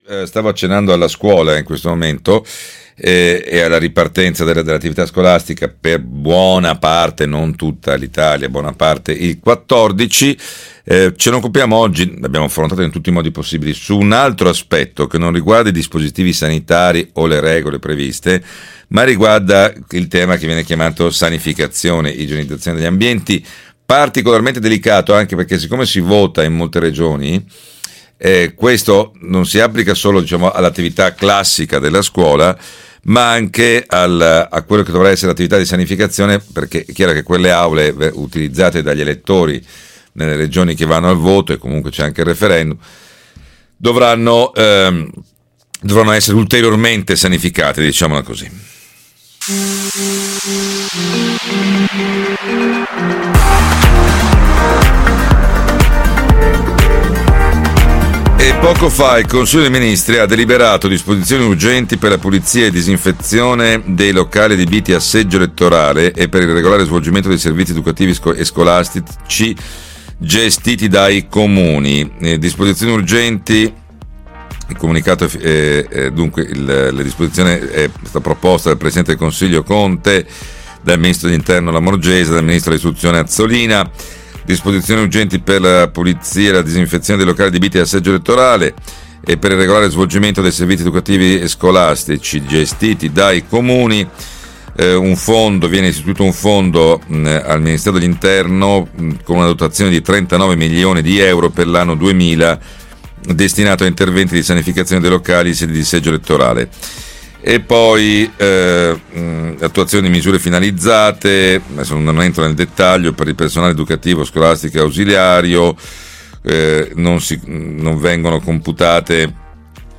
Scuola e sanificazione, l’intervista